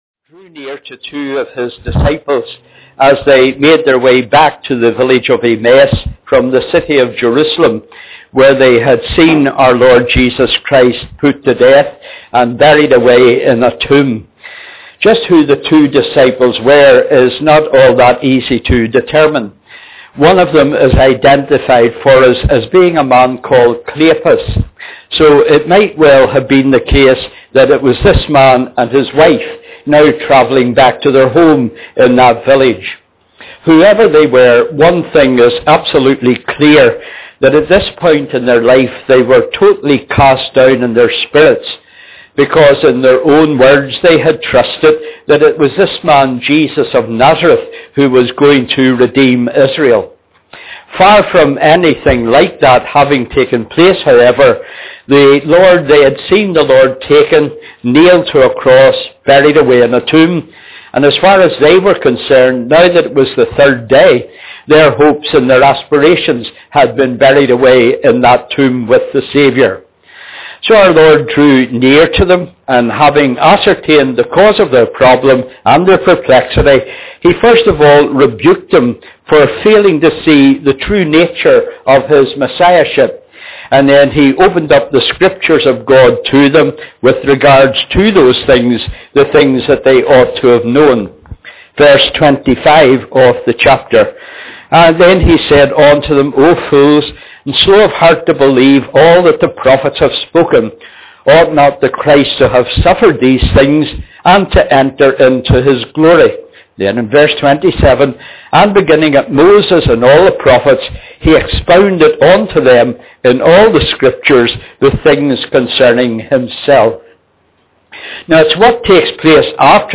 This was a combined service